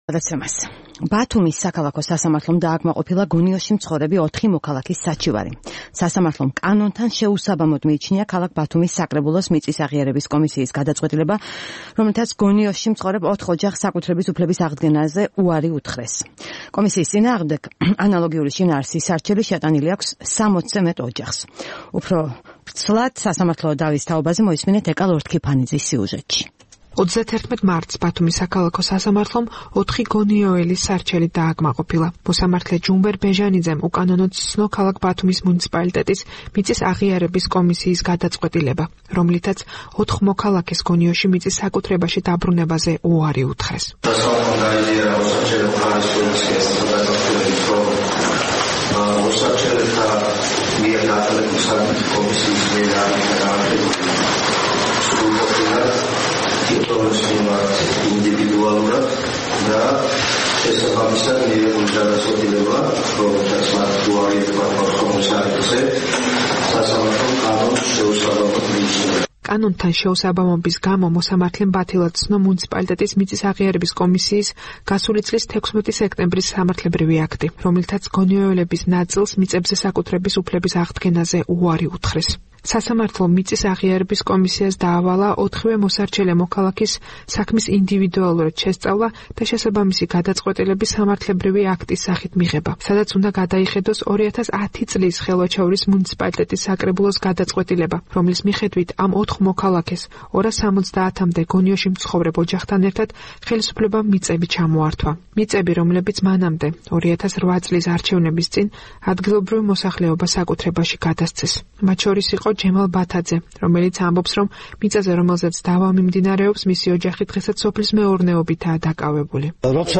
რეპორტაჟი ბათუმიდან